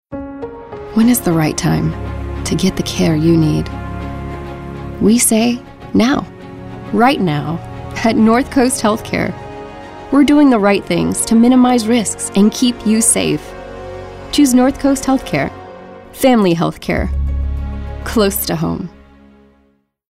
announcer, caring, compelling, concerned, thoughtful, warm, young adult